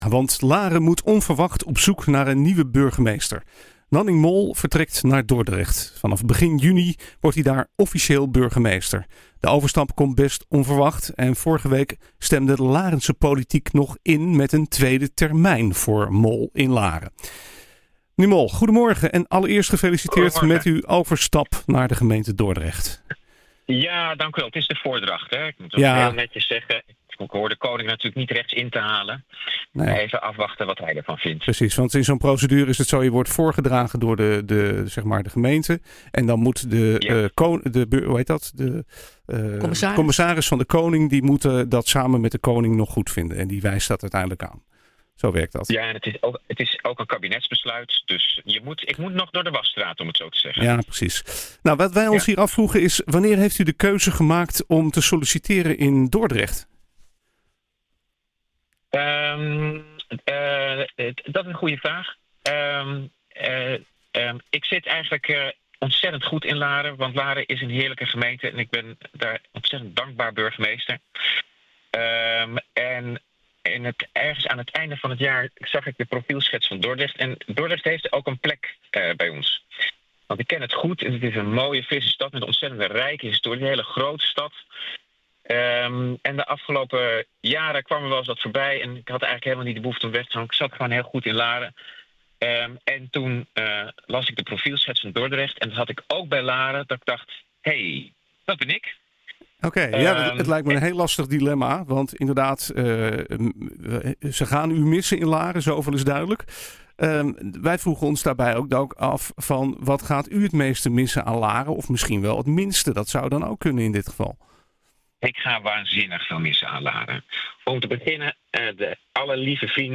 Een stad die hij goed kent, vertelt hij in gesprek met NH Gooi.